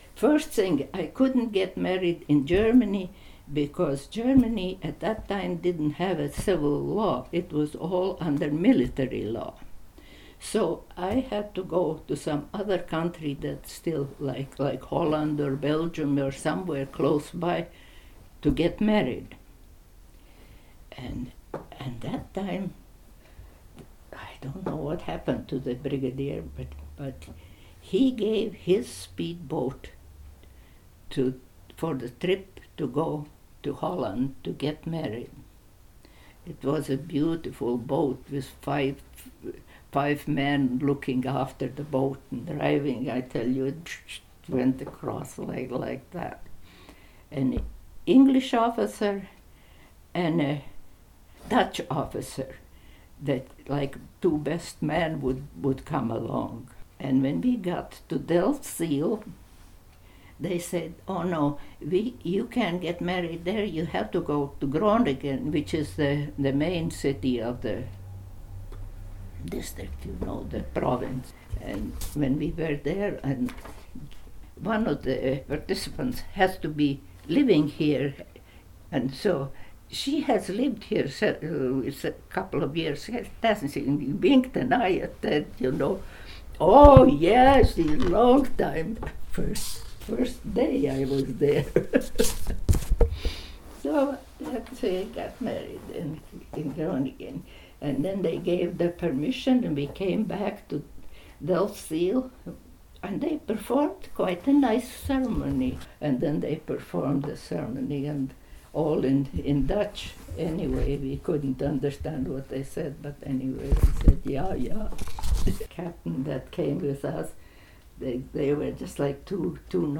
Interviewer (ivr)